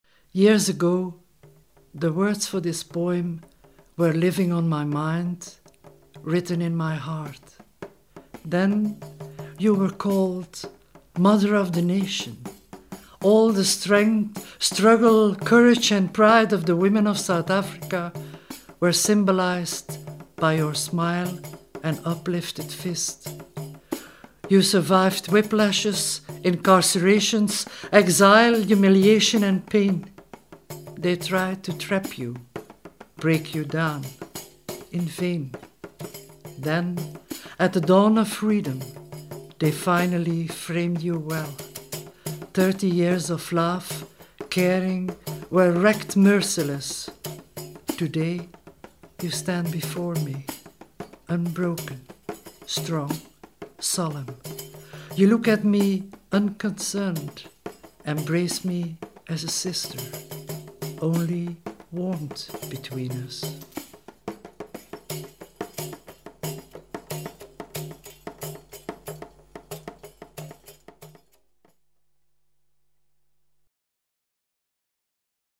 Spoken Word Section... ( Ladies )
Live Spoken Word - Click on the Audio Links Below: